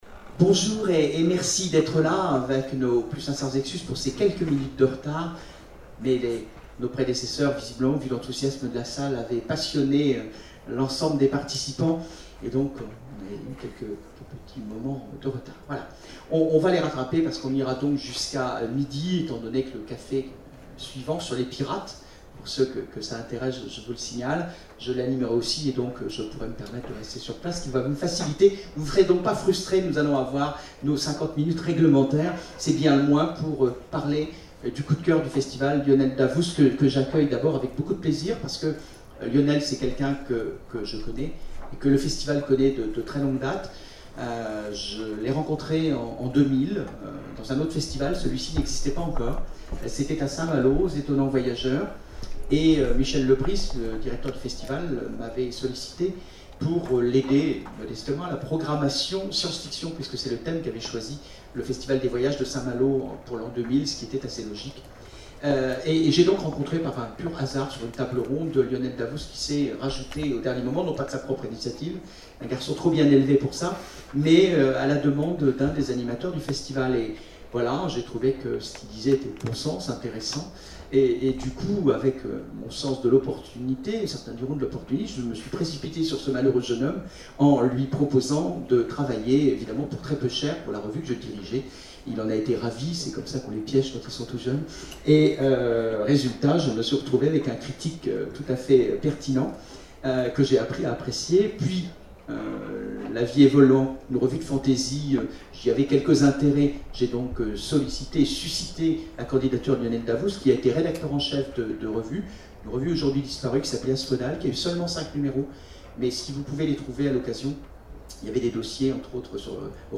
Conférence
Mots-clés Rencontre avec un auteur Conférence Partager cet article